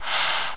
.17BreathIn.mp3